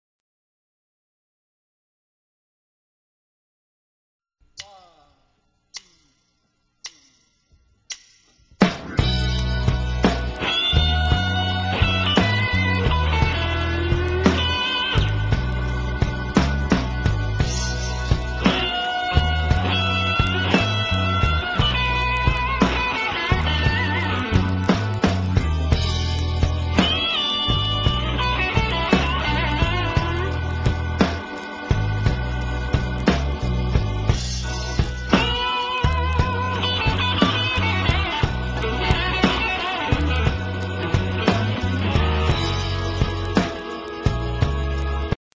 NOTE: Background Tracks 9 Thru 16